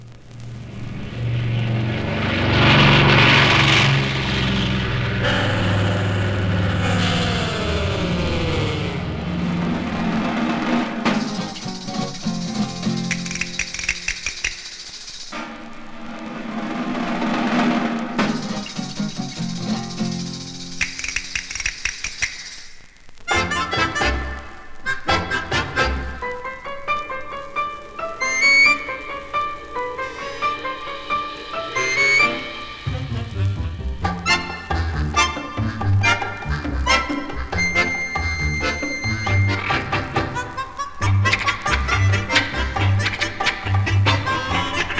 ハーモニカ奏者
針をのせると、ふんわりと気持ちほどける夢見心地サウンドが実に気持ちよいのですが、実は只者じゃないレコード。
Jazz, Easy Listening, Strange　USA　12inchレコード　33rpm　Stereo